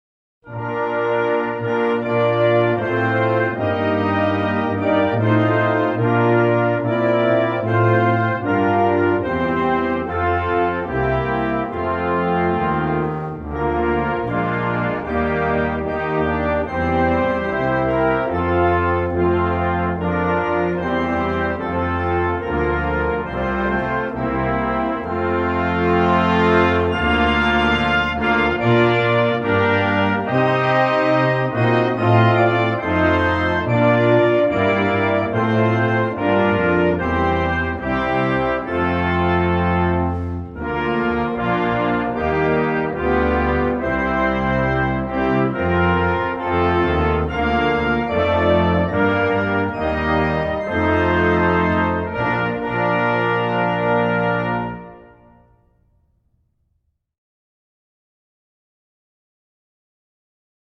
Antigua_and_Barbuda_National_Anthem.mp3